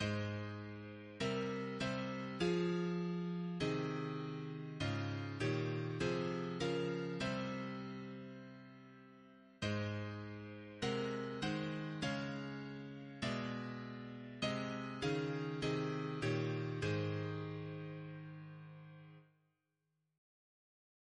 Double chant in A♭ Composer